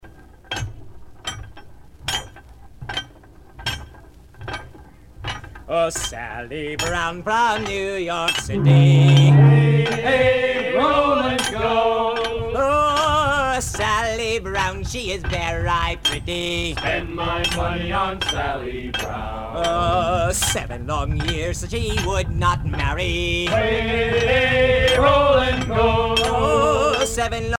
à virer au cabestan
circonstance : maritimes
Pièce musicale éditée